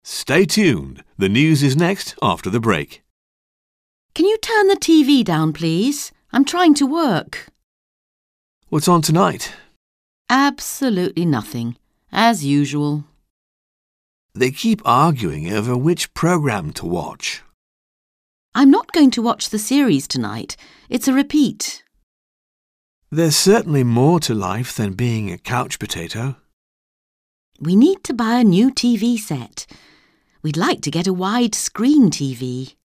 Un peu de conversation - La radio et la télévision